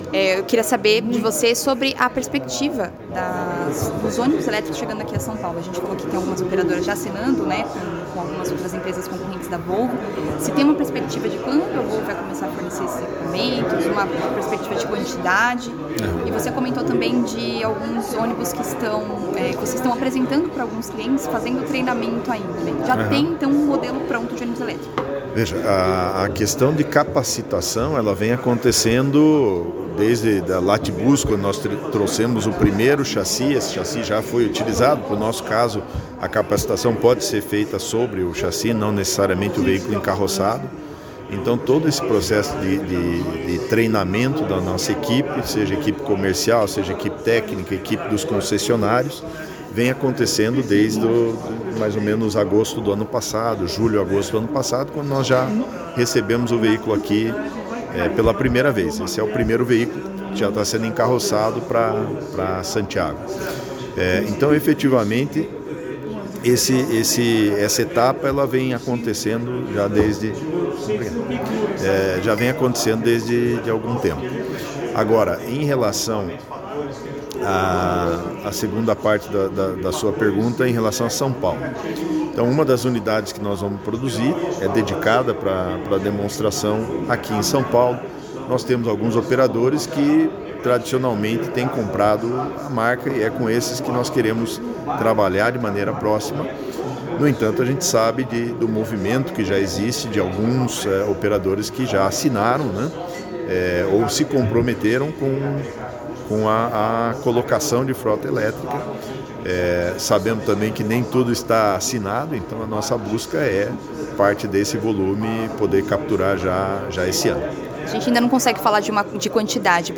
EXCLUSIVO/ENTREVISTA: Volvo confirma que vai apresentar ônibus elétrico para a capital paulista no segundo semestre de 2023